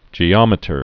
(jē-ŏmĭ-tər)